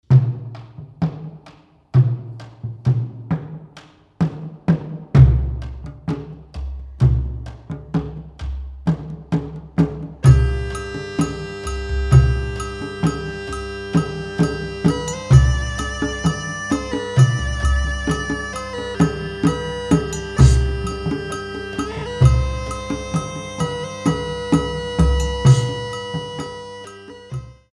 eleven beats